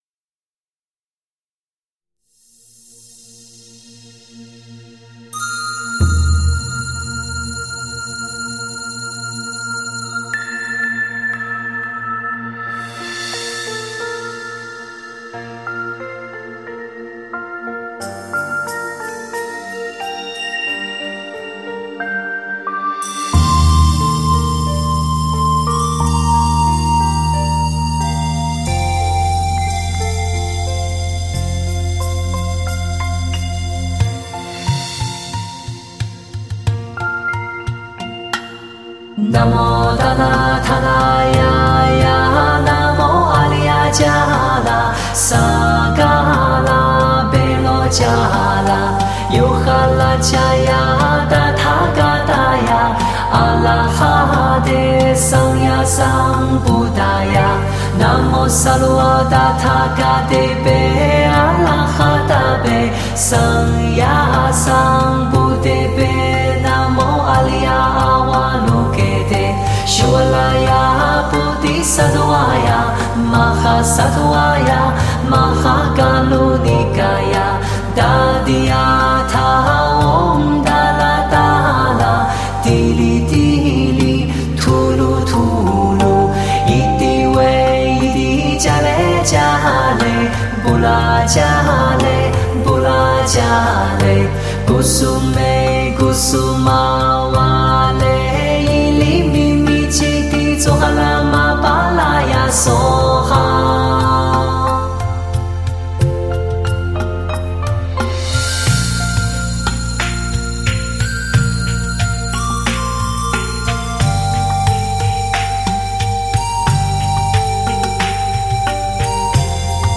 通透纯净如天籁般的声线
如无瑕丝绸般的唯美音色